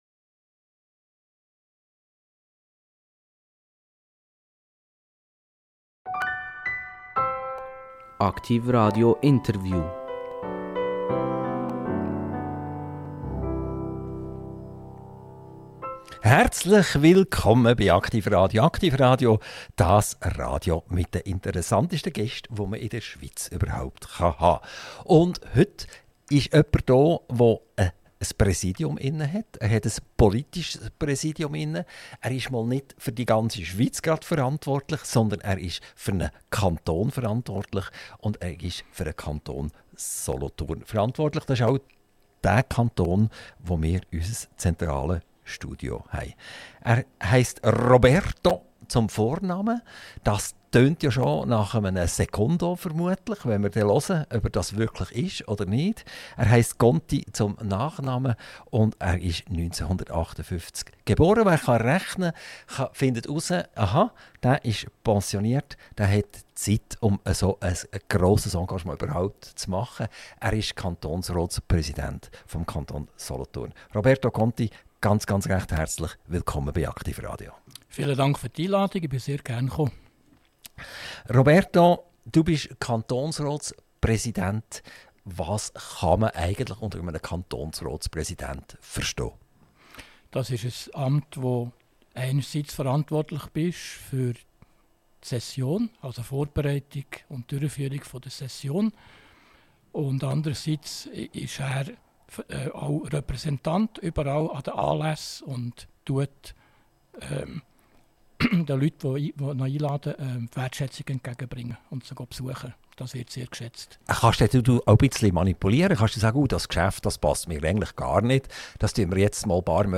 INTERVIEW - Roberto Conti - 19.11.2025 ~ AKTIV RADIO Podcast